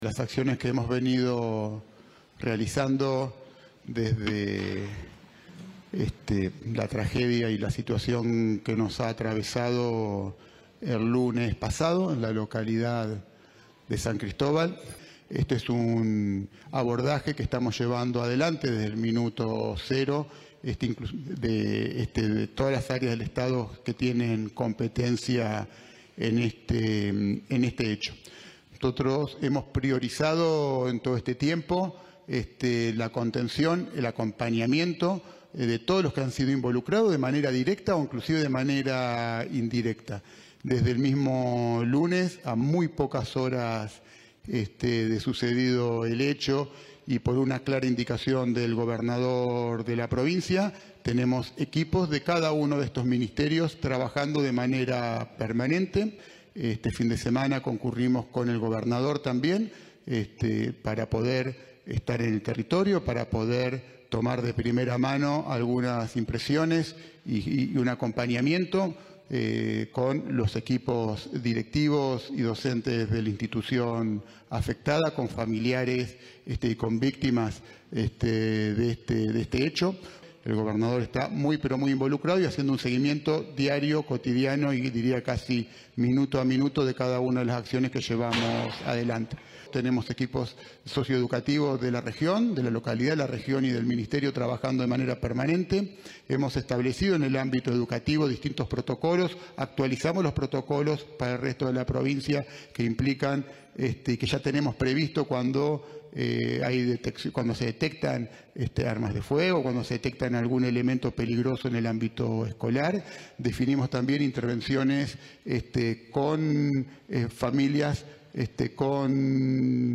Este lunes, en Rosario, Goity encabezó una conferencia de prensa acompañado de Daiana Gallo Ambrosis (secretaria de Gestión Territorial de Educación), Esteban Santantino (secretario de Análisis y Gestión de la Información del Ministerio de Justicia y Seguridad), Daniela León (secretaria de Niñez) y Liliana Olguín (subsecretaria de Salud Mental).